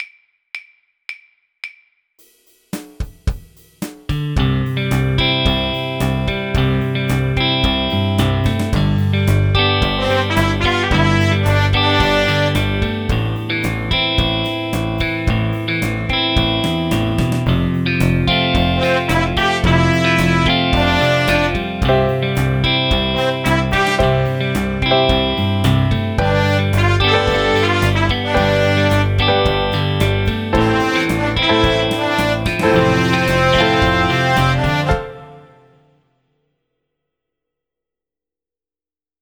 There are 10 lessons in this series building skills to create a variety of short tracks in GarageBand on the iPad.